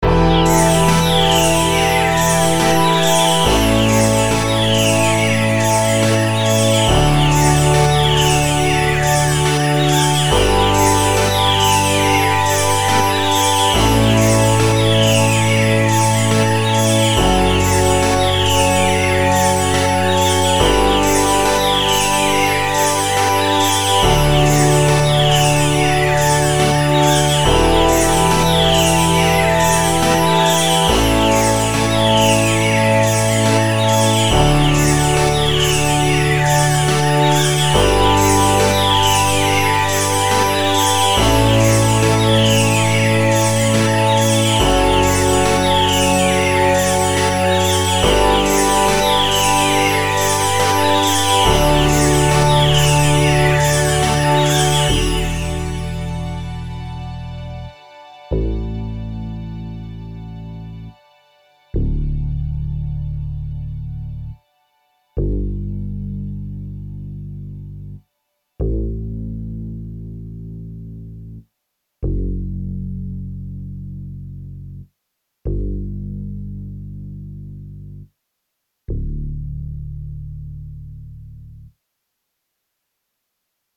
Für die Klangbeispiele habe ich mir eine Vorlage mit 8 Bars ausgesucht, diese dann in der DAW gedoppelt und mit unterschiedlichen Instrumenten belegt.
chillout-test-birds.mp3